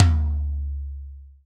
TOM TOM102.wav